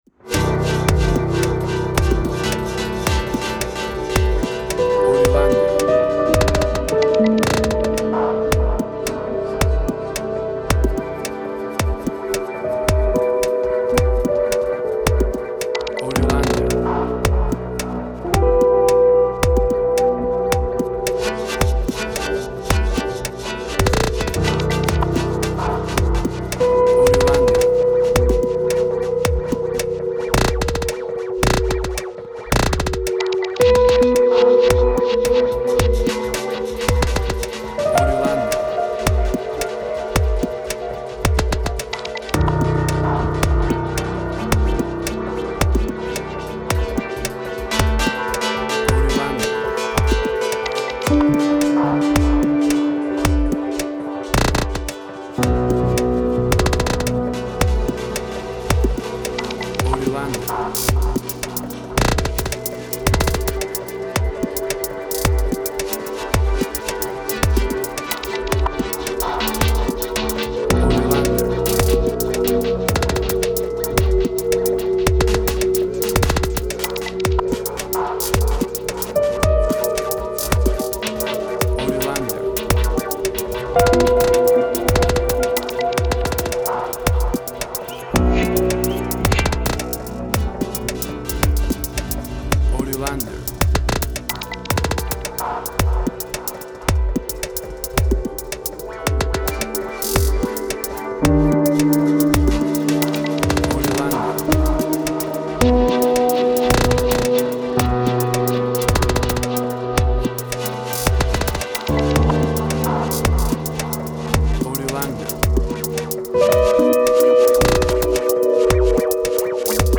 IDM, Glitch.
Tempo (BPM): 110